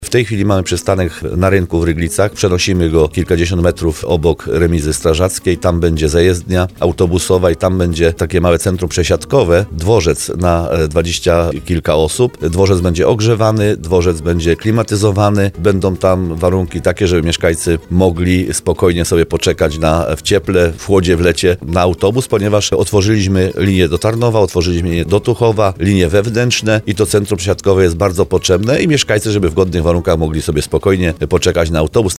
O planach na inwestycję poinformował burmistrz Paweł Augustyn, który gościł w rozmowie Słowo za Słowo.